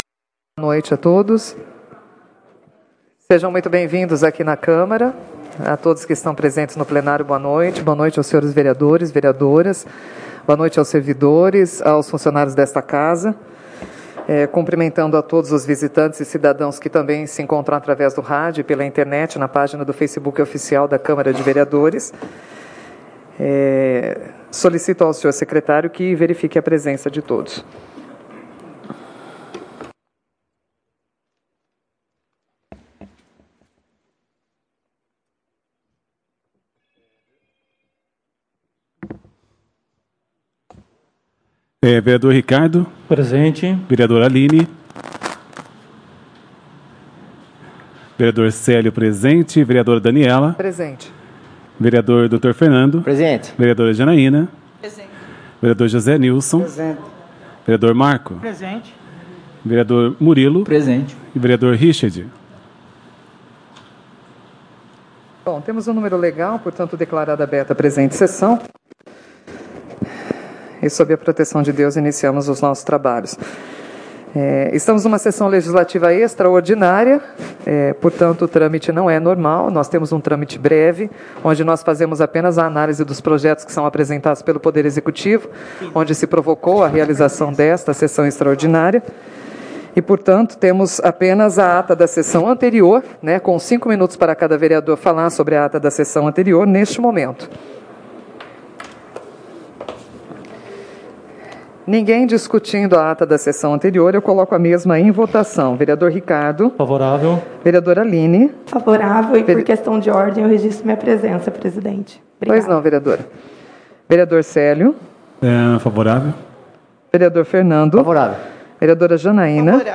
Sessões Extraordinárias